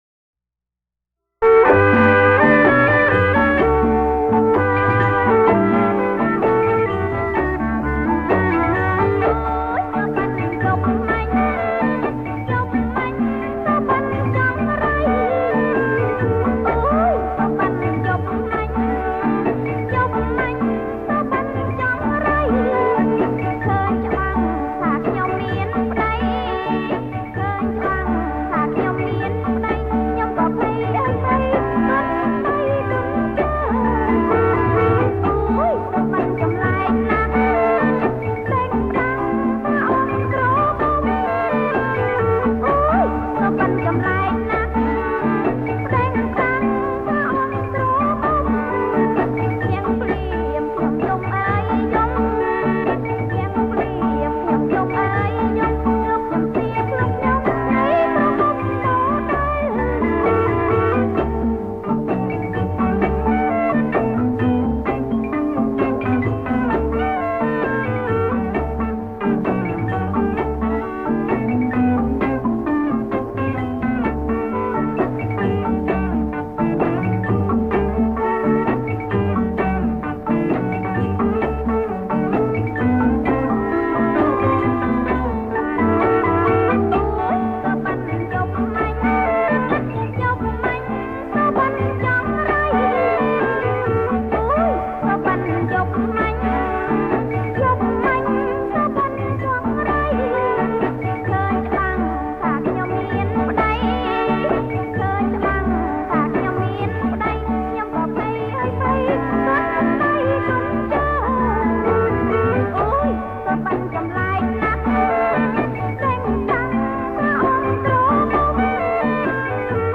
ប្រគំជាចង្វាក់ រាំវង់